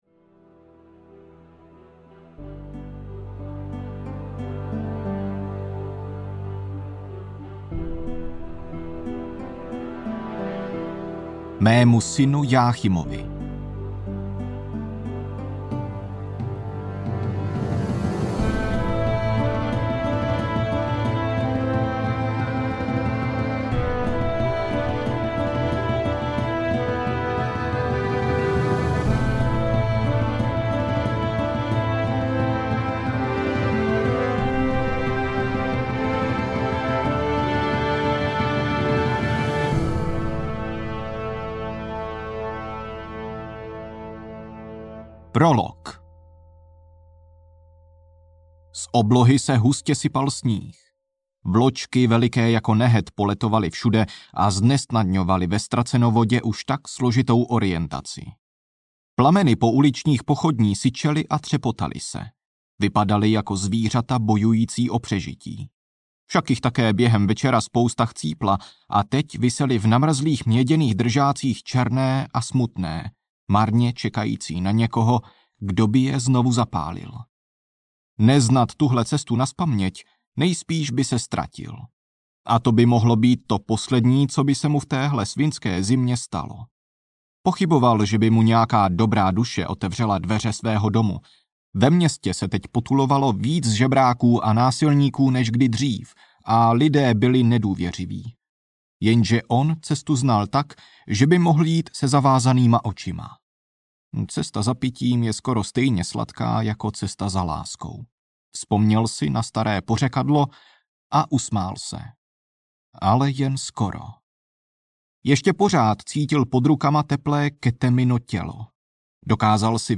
Třetí ucho audiokniha
Ukázka z knihy